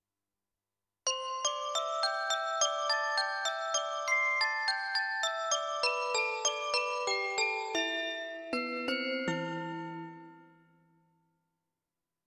21. I SUONI - GLI STRUMENTI XG - GRUPPO "SYNTH EFFECTS"
13. Glocken Chime
XG-12-13-GlockenChime.mp3